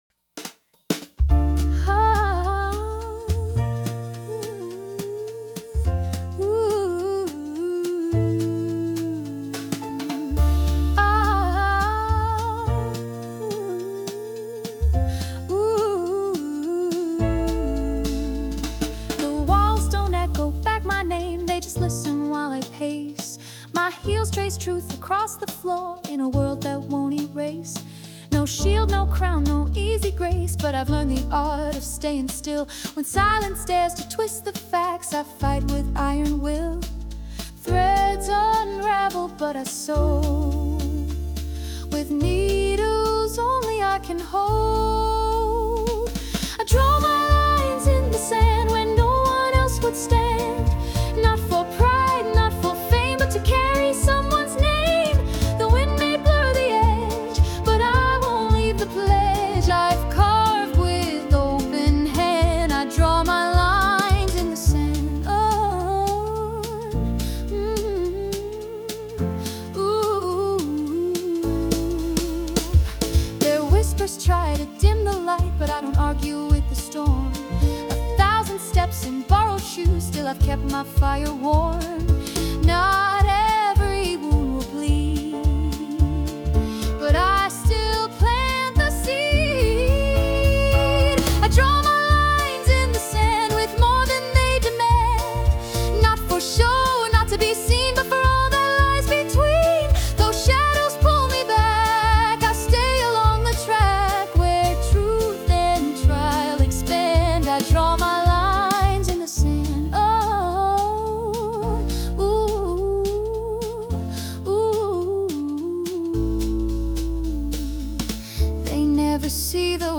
洋楽女性ボーカル著作権フリーBGM ボーカル
女性ボーカル洋楽洋楽 女性ボーカルアップテンポジャズおしゃれ切ない
著作権フリーオリジナルBGMです。
女性ボーカル（洋楽・英語）曲です。